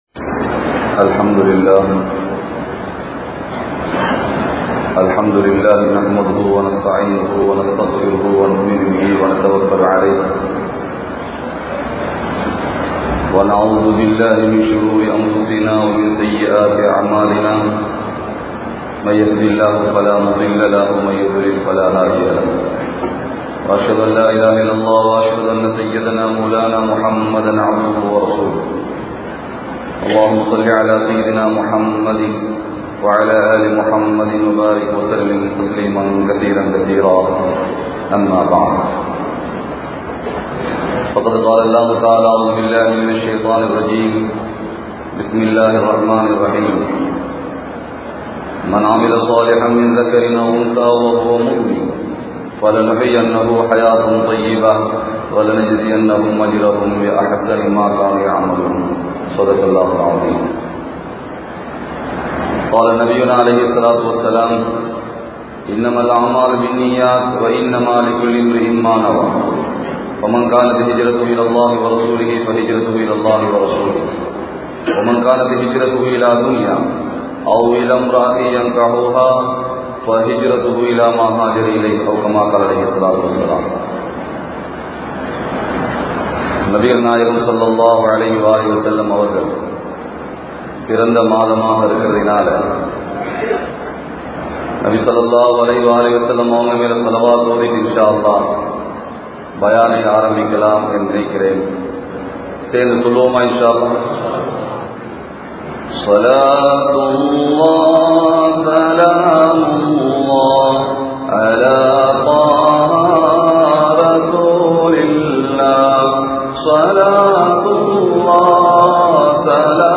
Nabi(SAW)Avarhalin Sirappuhal (நபி(ஸல்) அவர்களின் சிறப்புகள்) | Audio Bayans | All Ceylon Muslim Youth Community | Addalaichenai